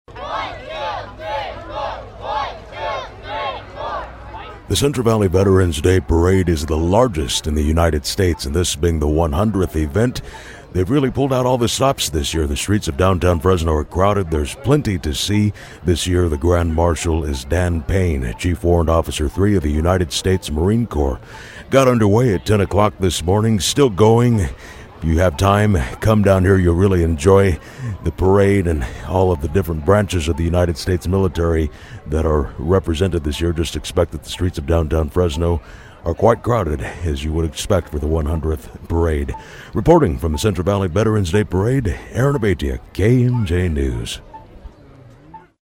FRESNO, Calif. (KMJ) — The 100th Central Valley Veterans’ Day Parade got underway Monday in Downtown Fresno.
AA-VETERANS-PARADE.mp3